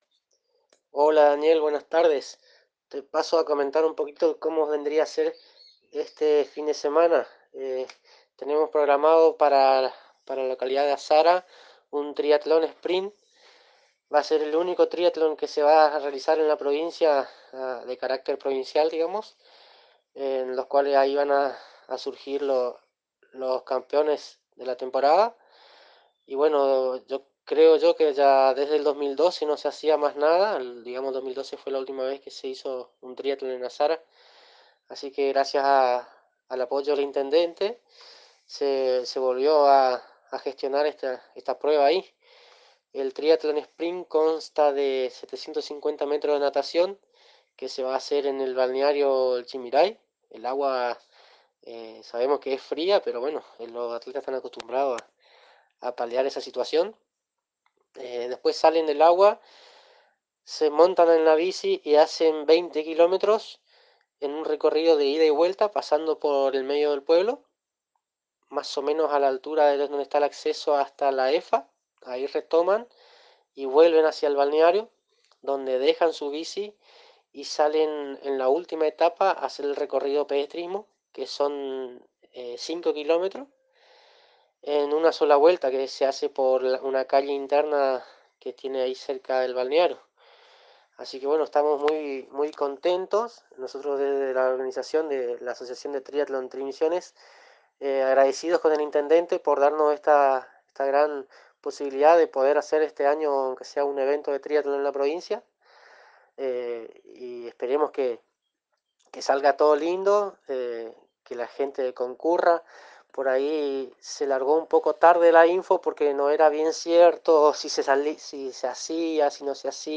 En la tarde de este lunes en una comunicación telefónica exclusiva para la Agencia de Noticias Guacurarí